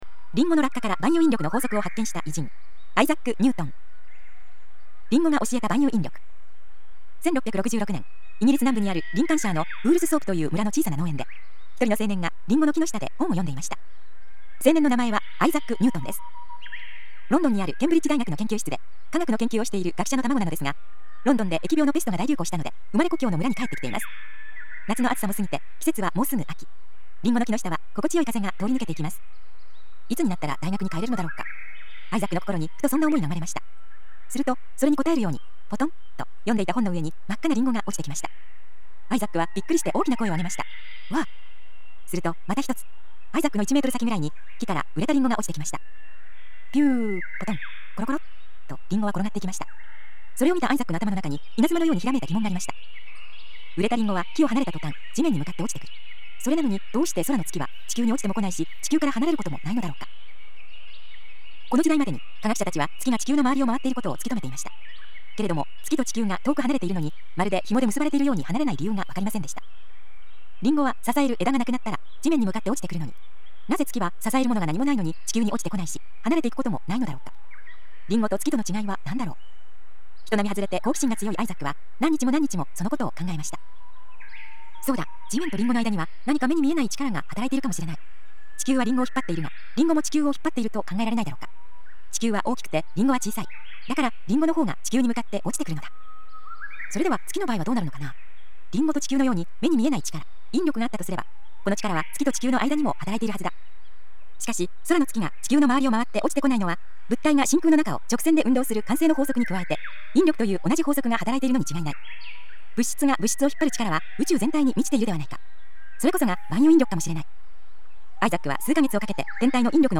森の中の鳥のさえずりなどの自然音がバックに流れる中、独自のコンテンツや価値ある講演などの音声を、１倍速から無理なく段階的に高速再生し、日々音楽のように楽しく聴くことによって、年齢に関係なく潜在意識を “脳力全開”させていくシステムです
歴史上の偉人たちの話を、わかりやすい文章と穏やかな語りでお伝えする朗読ＣＤです